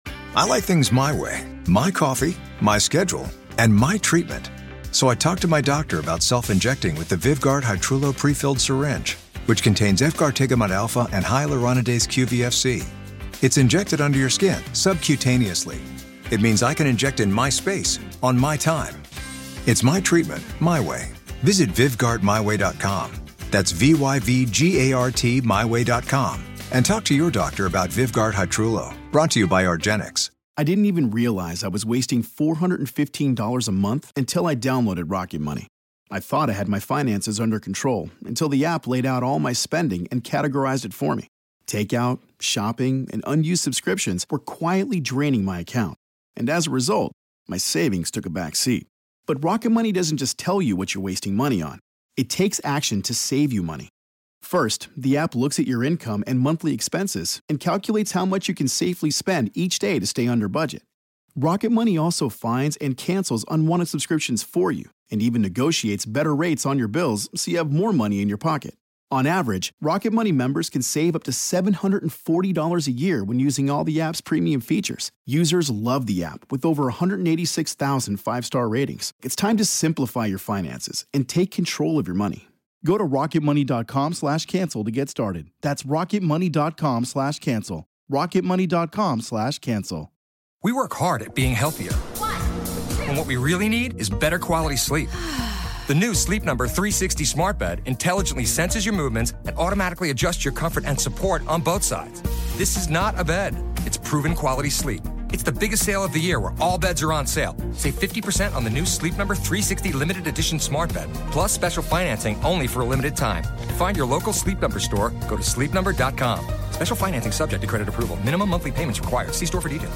Outro Instrumental